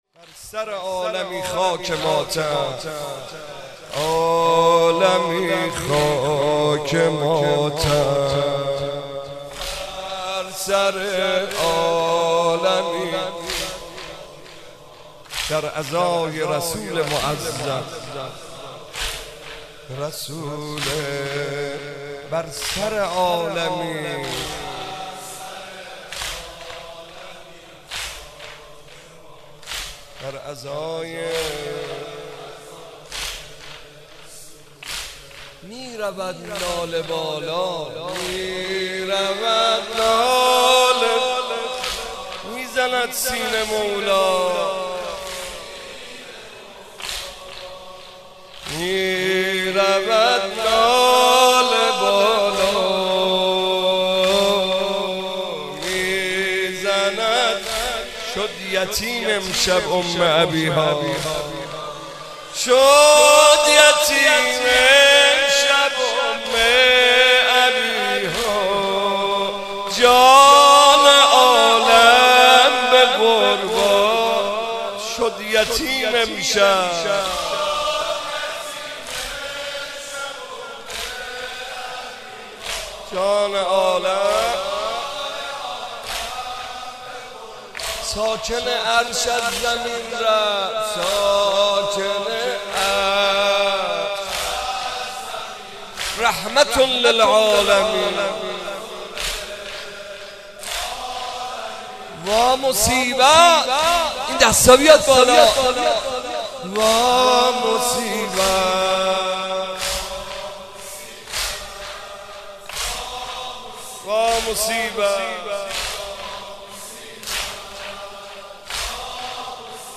مداحی شهادت پیامبر اکرم (ص) با صدای حاج محمدرضا طاهری